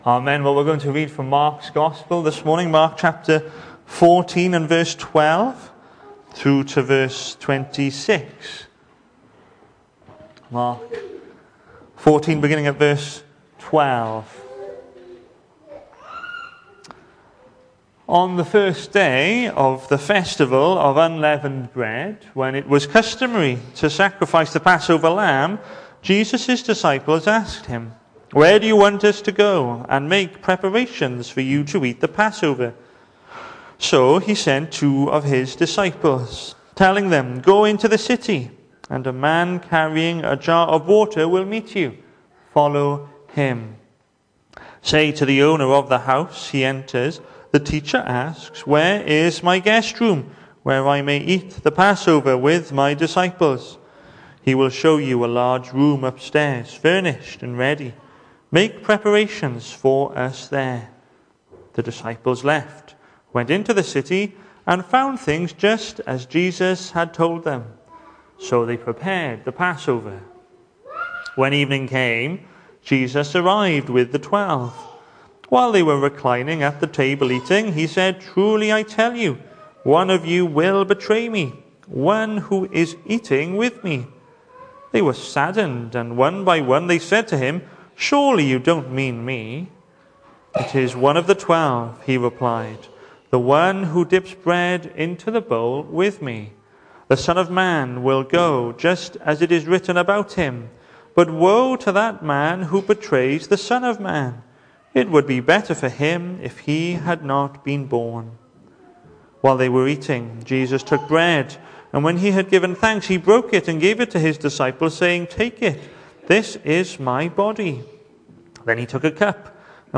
Hello and welcome to Bethel Evangelical Church in Gorseinon and thank you for checking out this weeks sermon recordings.
The 19th of October saw us host our Sunday morning service from the church building, with a livestream available via Facebook.